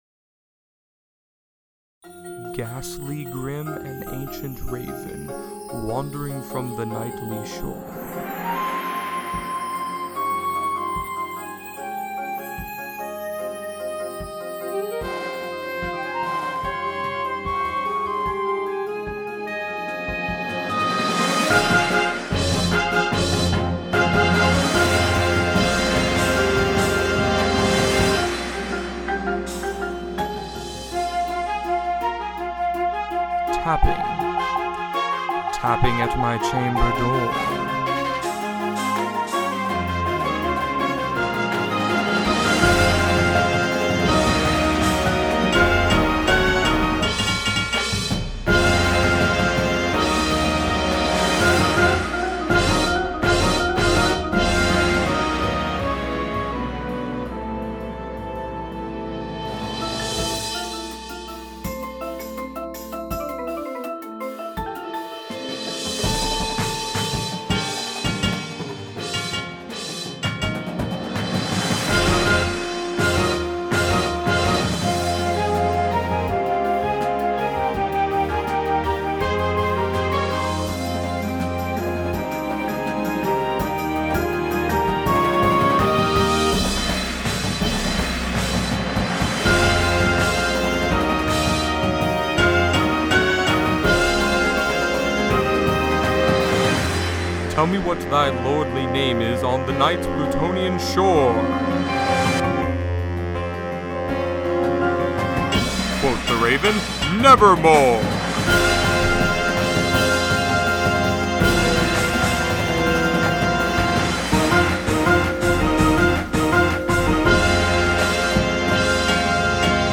• Tuba
• Snare Drum